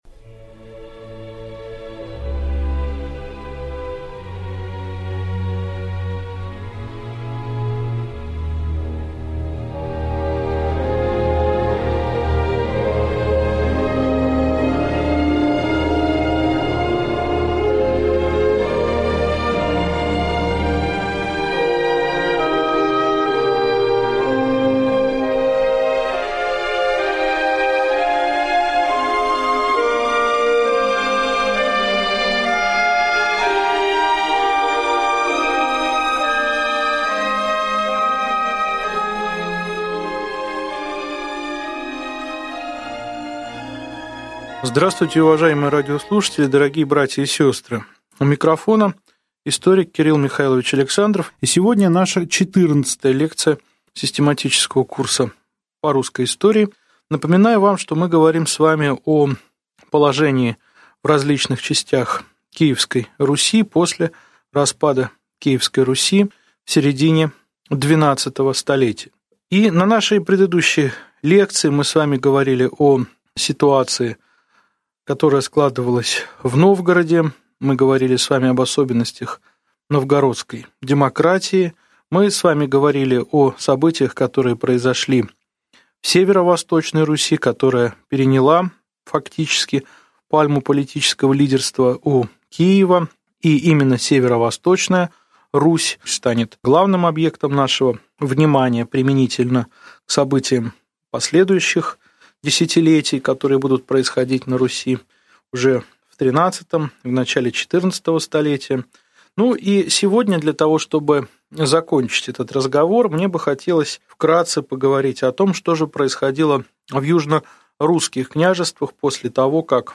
Аудиокнига Лекция 14. Галицко-Волынская земля. Культура древней Руси в домонгольский период | Библиотека аудиокниг